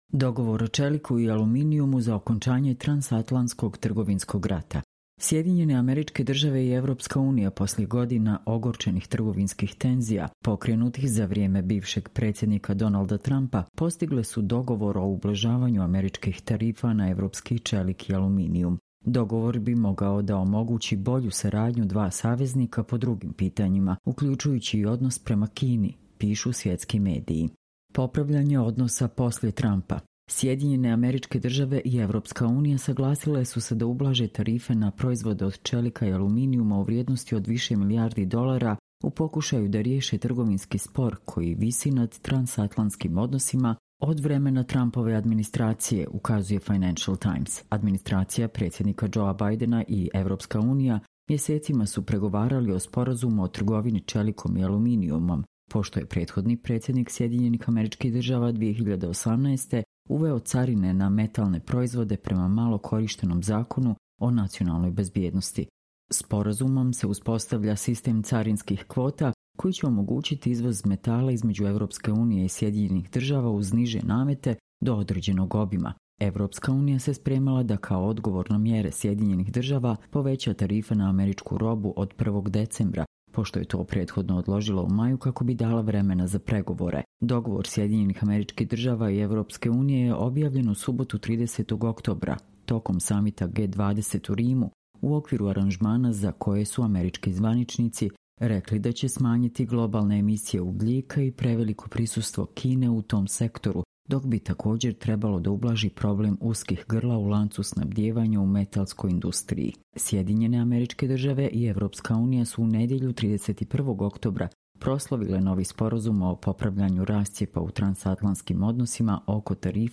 Čitamo vam: Dogovor o čeliku i aluminijumu za kraj transatlantskog trgovinskog rata